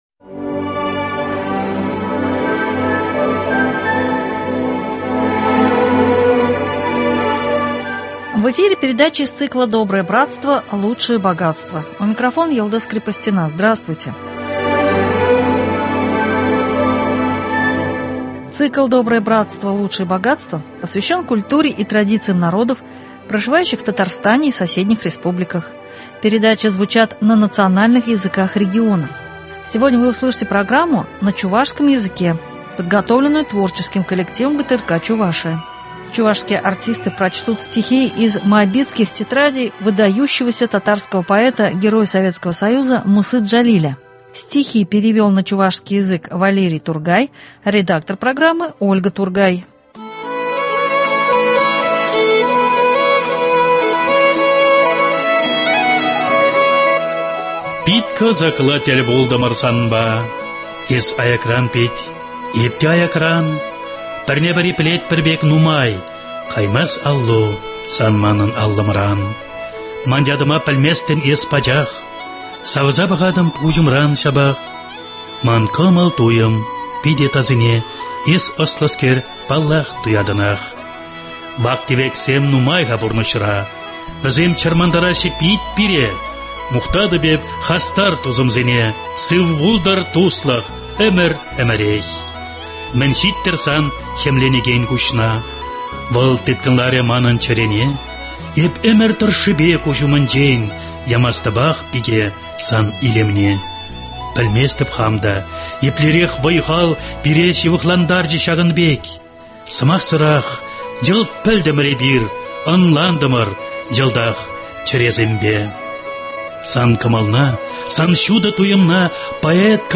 Чувашские артисты прочтут стихи из Моабитских тетрадей выдающегося татарского поэта, героя Советсткого Союза Мусы Джалиля..